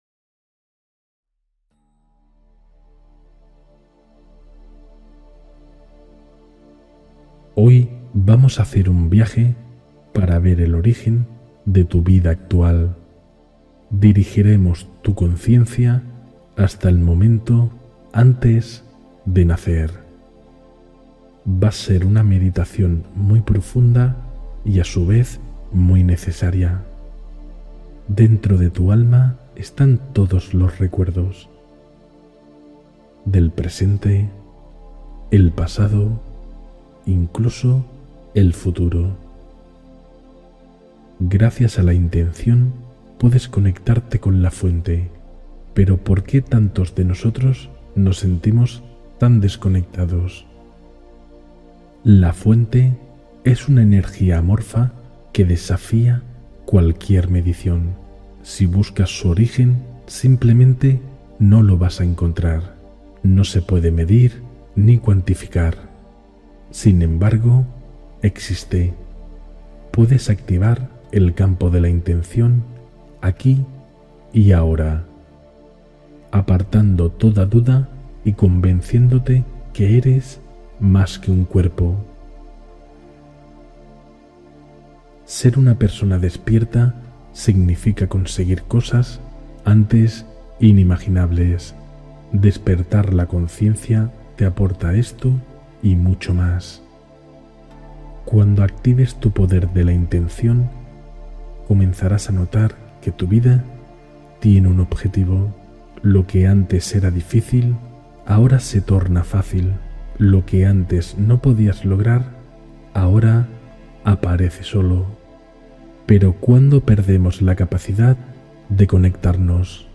Sana desde el origen emocional con esta meditación profunda – Día 4